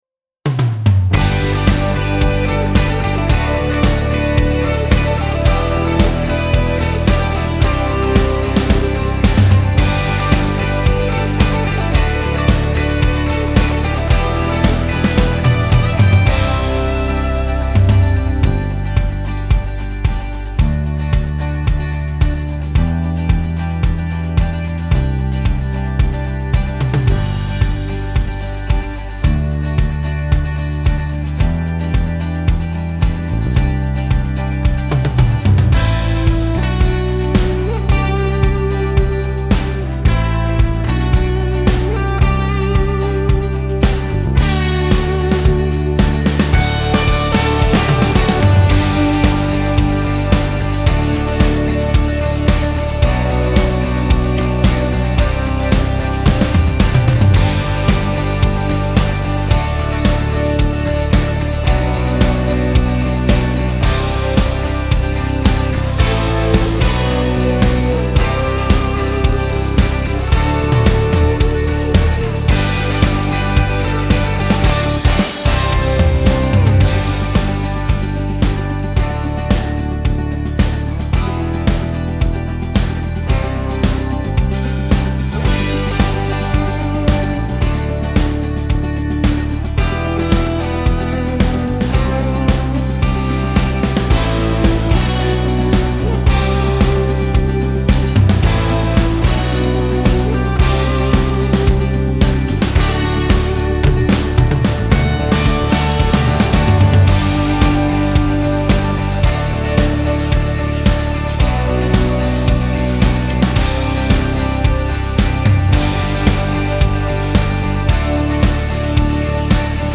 Guitars
Keyboard
Drums